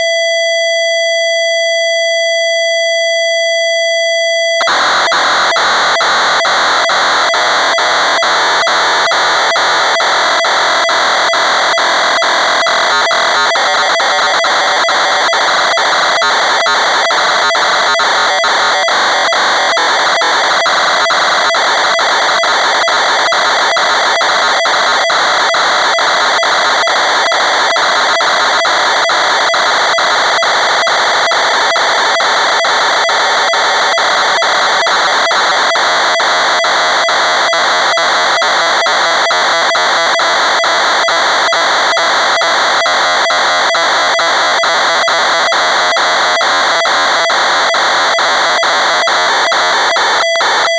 TIDiceRollerTape.wav